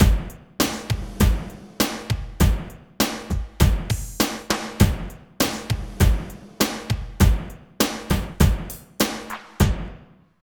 12 DRUM LP-R.wav